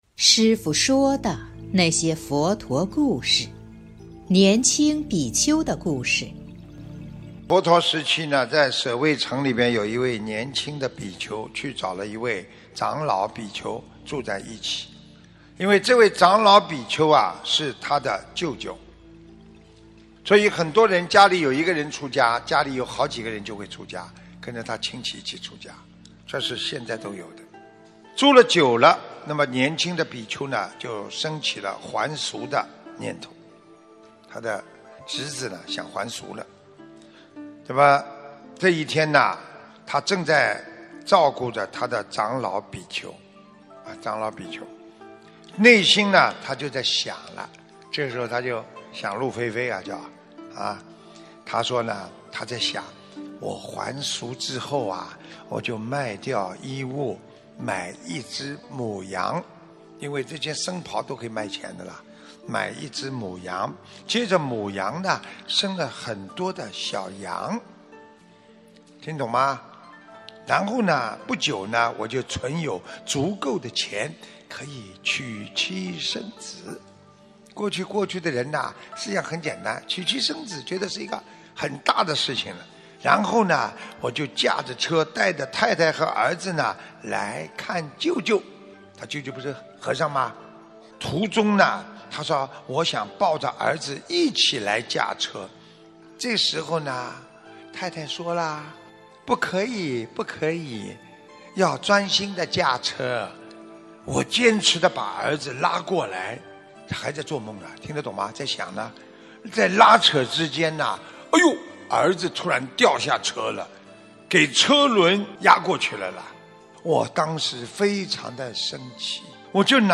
音频：《年青比丘的故事》师父说的那些佛陀故事！【师父原声音】